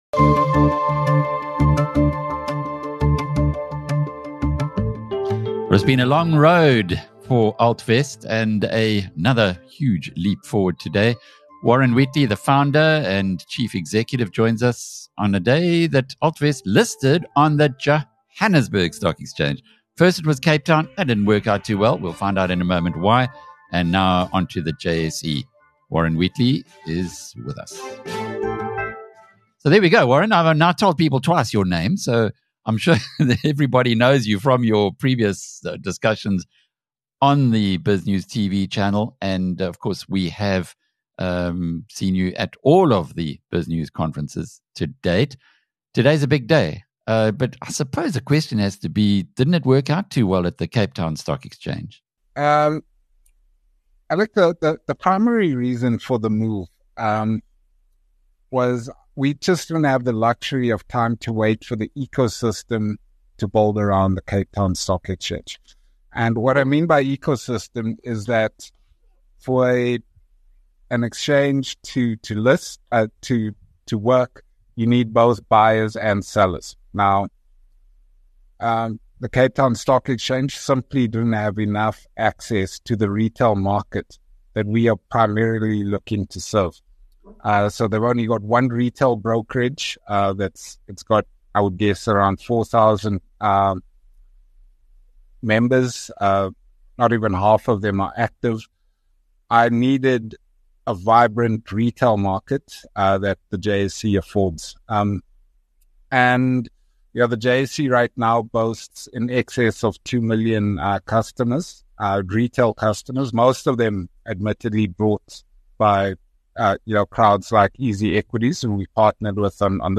In this BizNews interview